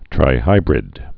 (trī-hībrĭd)